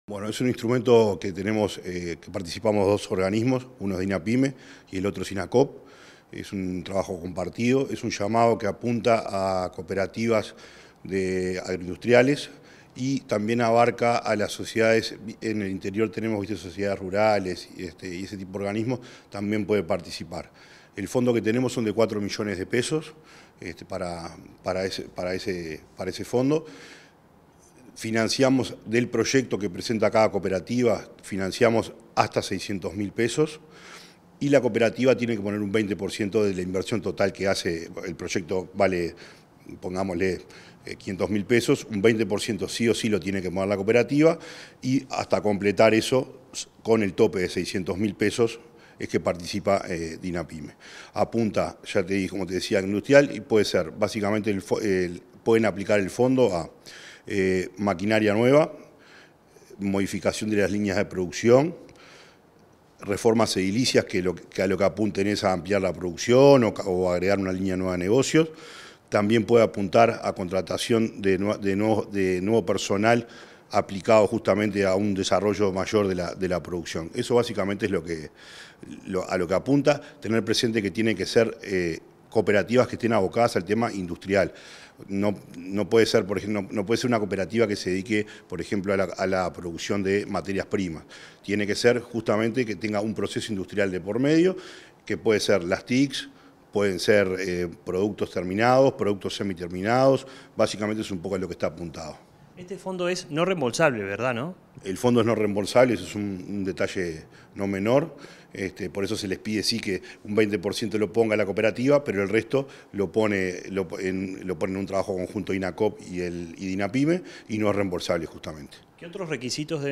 Entrevista al director de Dinapyme, Gonzalo Maciel